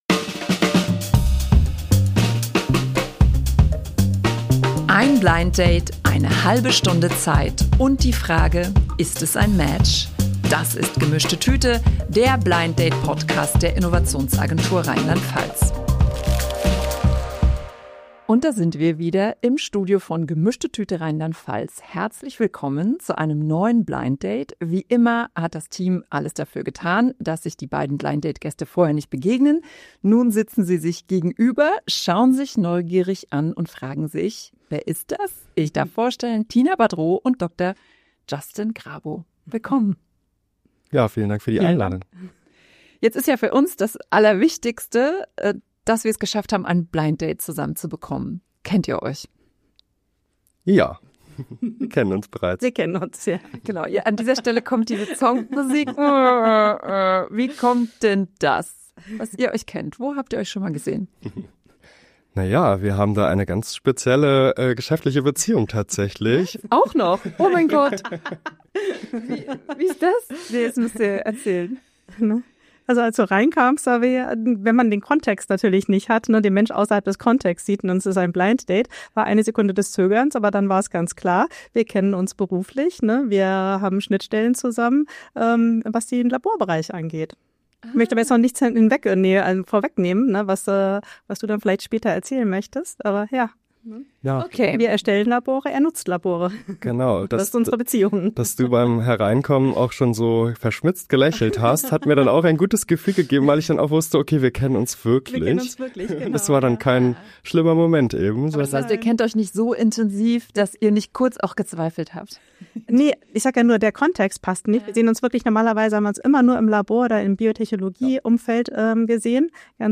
Im Podcast der Innovationsagentur RLP treffen sich zwei Persönlichkeiten, die sich bisher nicht kennen, und tauschen sich über ihre unterschiedlichen Perspektiven aus. Das Besondere: Sie bringen ein Geschenk mit, das etwas über sie erzählt.